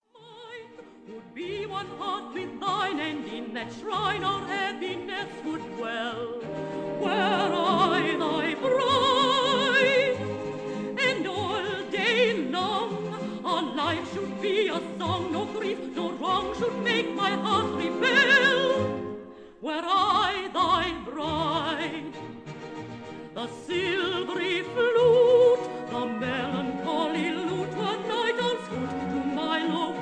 contralto